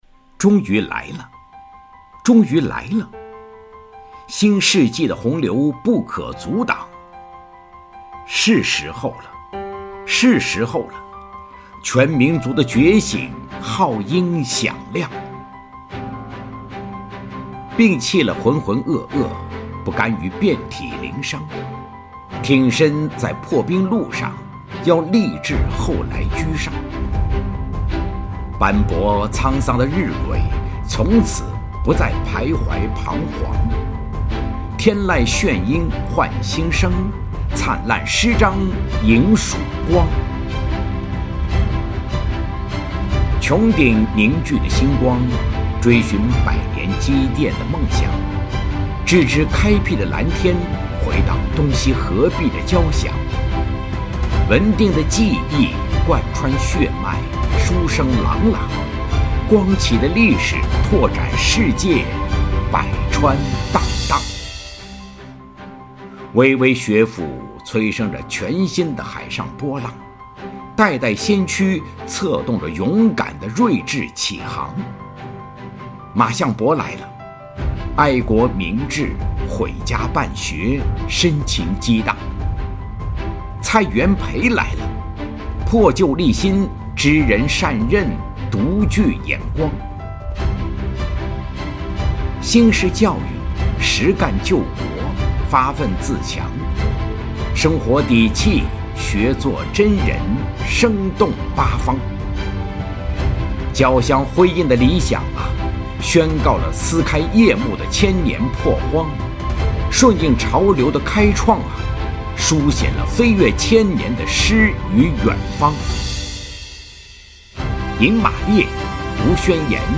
有声诗文《日晷的觉醒》